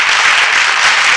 Applauding (short) Sound Effect
Download a high-quality applauding (short) sound effect.
applauding-short.mp3